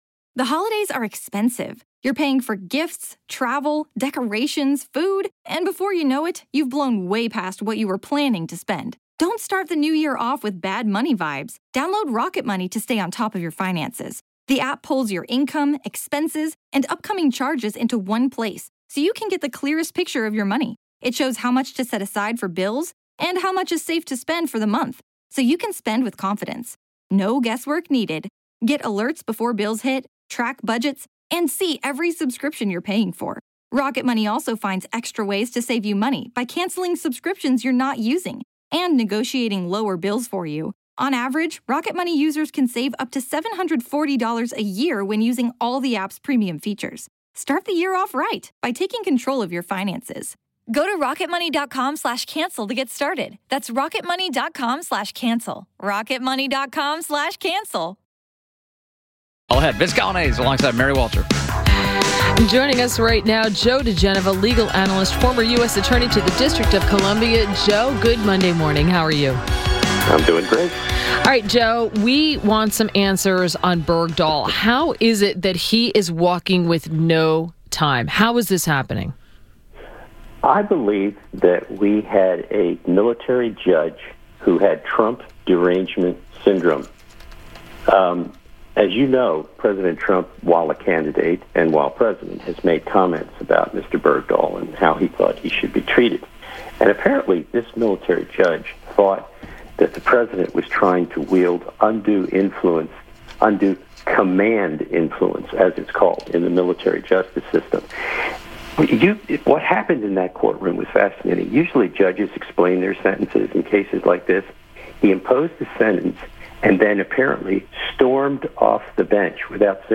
INTERVIEW — JOE DIGENOVA – legal analyst and former U.S. Attorney to the District of Columbia – discussed Bergdahl walking and the latest on the Mueller investigations.